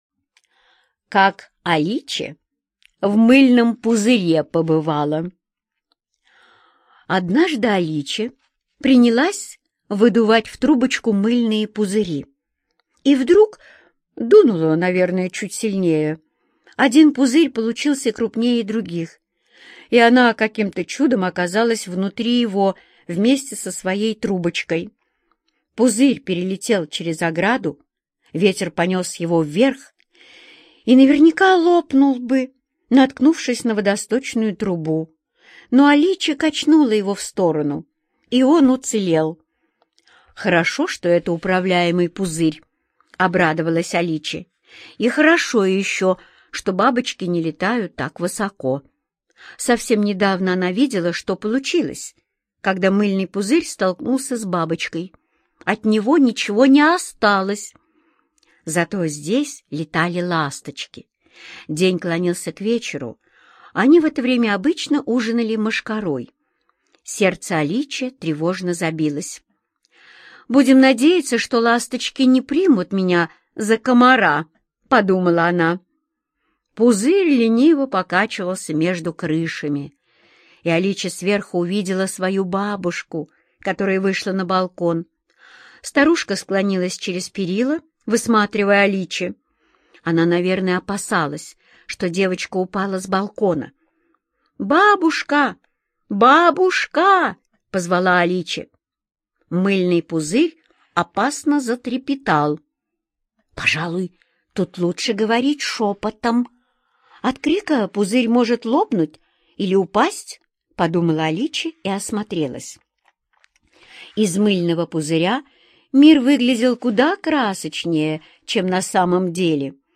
Слушайте Как Аличе в мыльном пузыре оказалась - аудиосказку Родари Д. Однажды Аличе надувала мыльные пузыри и попала внутрь большого пузыря.